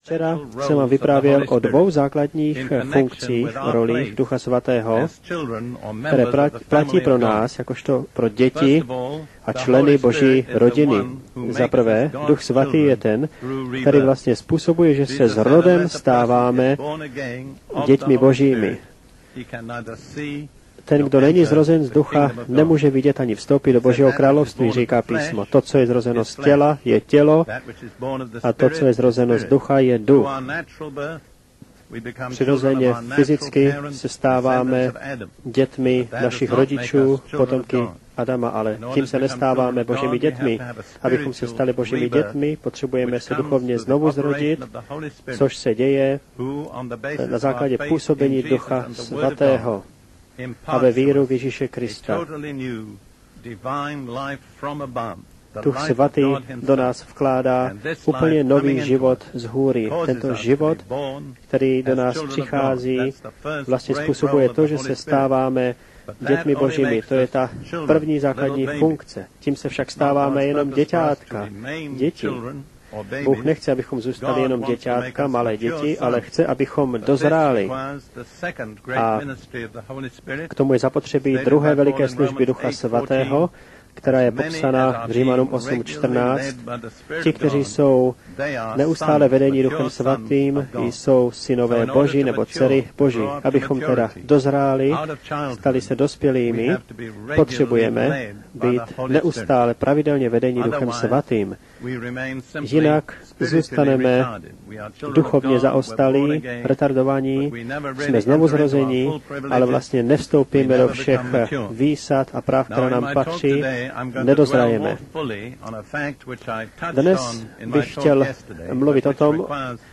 Z rádiového vysílání
s překladem do češtiny – Jak být veden Duchem svatým Vypadá to